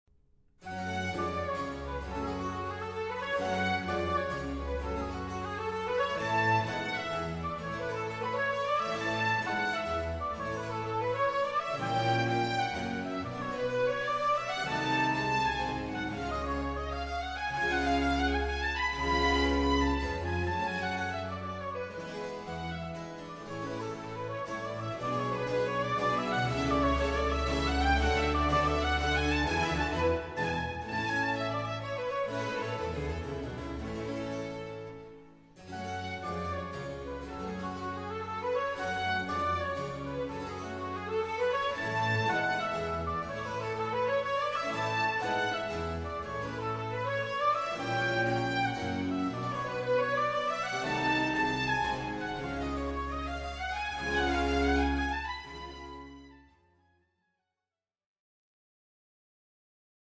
mezzo-soprano
baroque repertoire